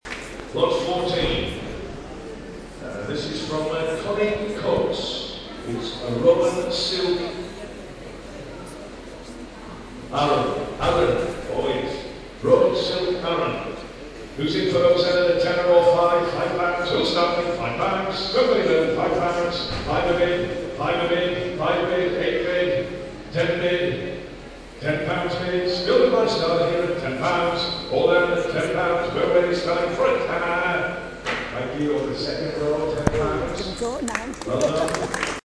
Sheep Auction
33935-sheep-auction.mp3